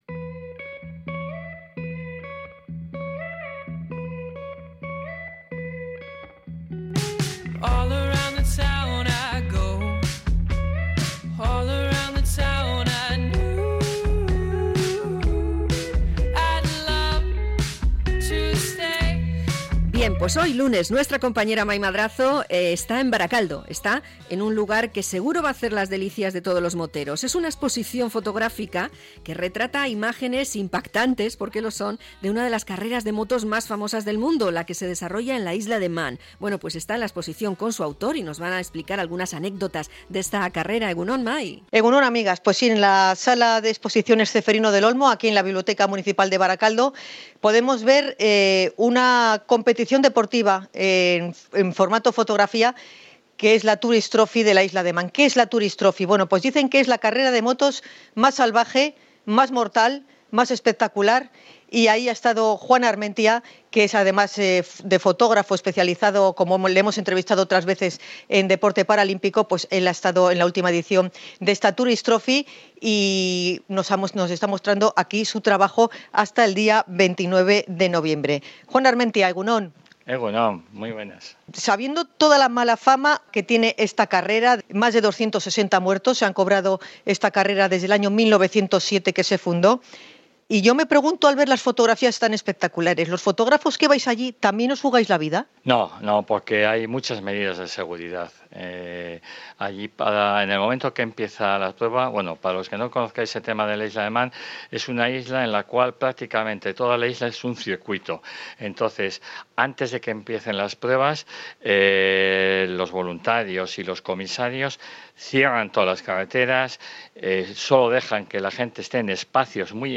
en la exposición "Viaje por TT Isla de Man"